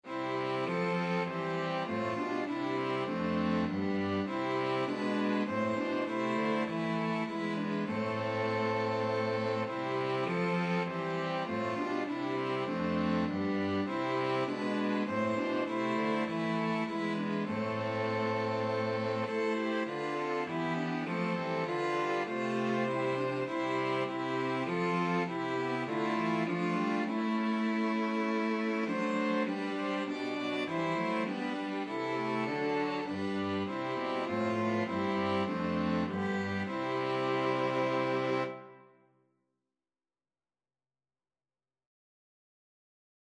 Free Sheet music for String Quartet
Violin 1Violin 2ViolaCello
C major (Sounding Pitch) (View more C major Music for String Quartet )
4/4 (View more 4/4 Music)
String Quartet  (View more Easy String Quartet Music)
Classical (View more Classical String Quartet Music)